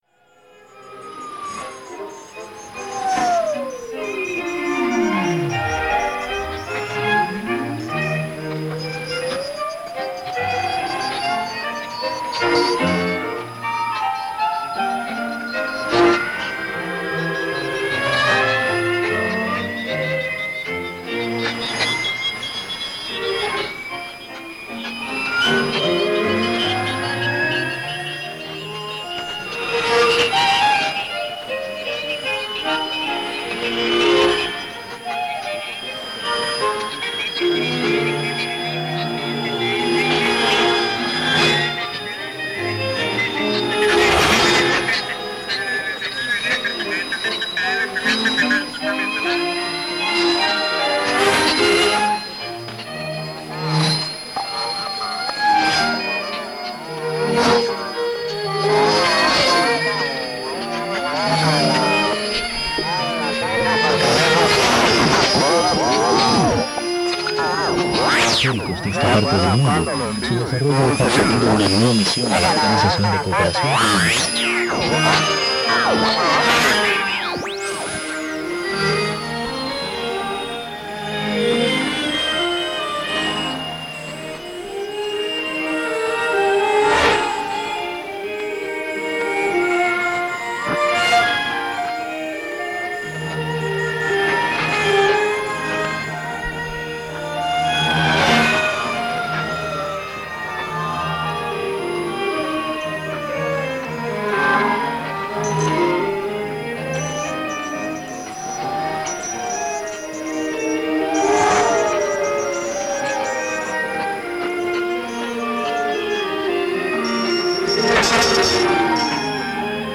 Otro de los sonidos en peligro de extinción es la radio de onda corta pues con sus perturbaciones atmosféricas, ruidos generados por computadoras y otros artefactos eléctricos además de las múltiples opciones a través de Internet hacen de la radio de onda corta un medio que ha perdido su popularidad de antaño.
Creemos que esos “ruidos aleatorios” hacían que cada emisión y recepción de una señal radio analógica fuera única e irrepetible.